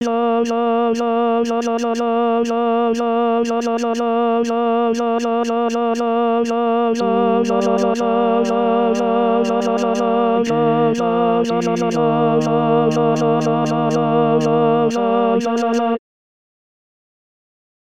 La Ballata del soldato basses 2 haut.mp3